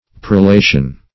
Prolation \Pro*la"tion\, n. [L. prolatio: cf. F. prolation.]